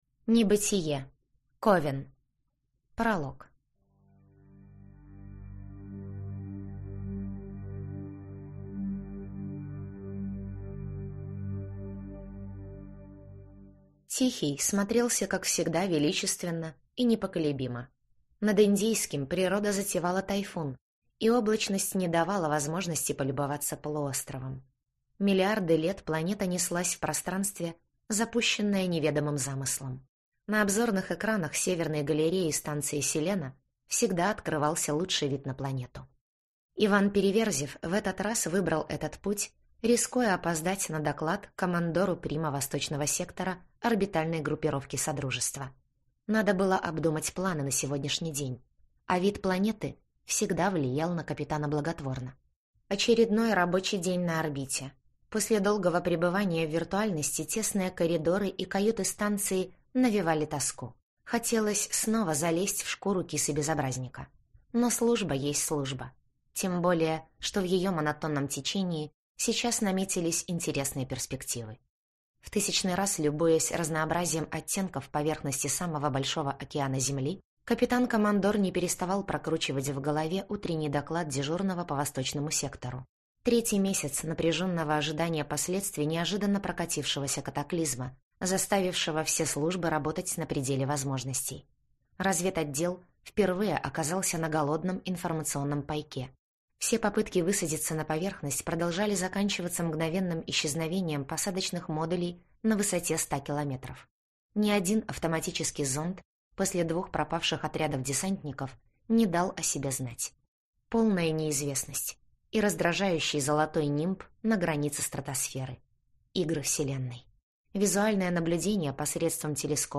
Аудиокнига Небытие. Ковен | Библиотека аудиокниг
Прослушать и бесплатно скачать фрагмент аудиокниги